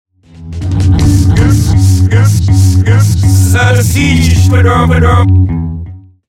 Genere: hip hop